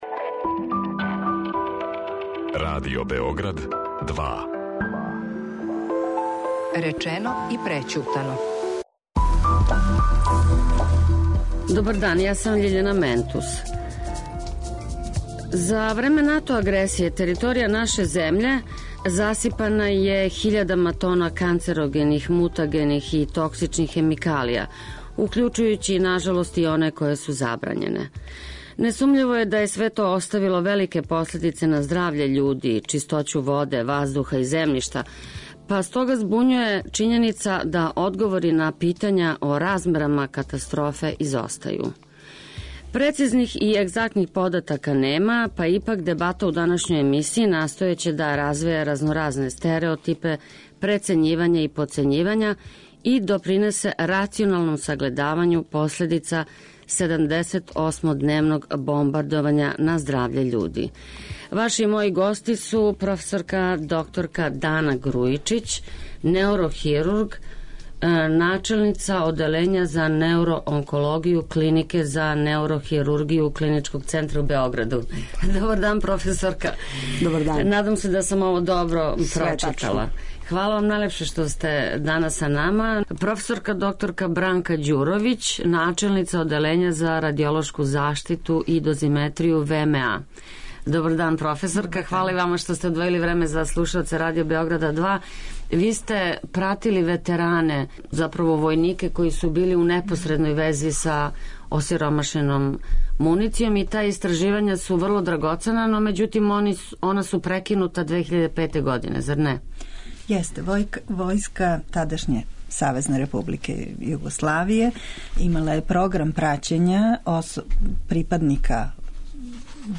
Прецизних, егзактних података нема, па ипак дебата у данашњој емисији настојаће да развеје разноразне стереотипе, прецењивања и потцењивања и допринесе рационалнијем сагледавању последица 78-дневног бомбардовања пре 16 година...